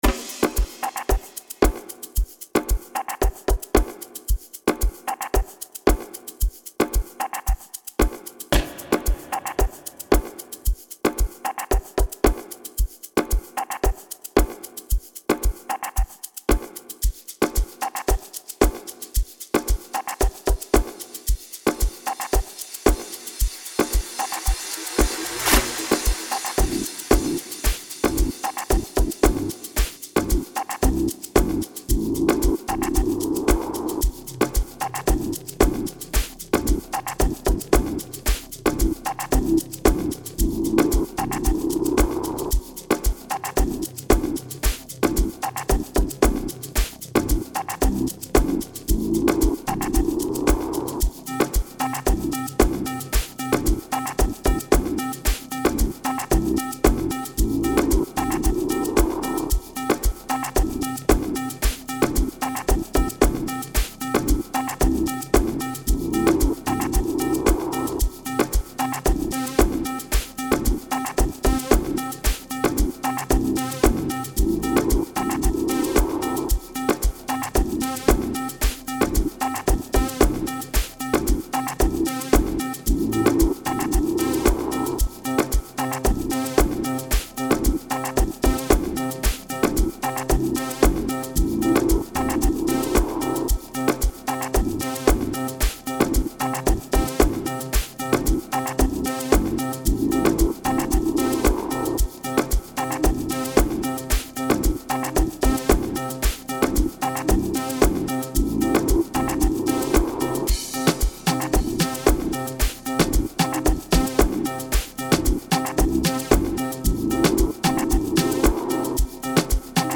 04:53 Genre : Amapiano Size